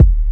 Kick 25.wav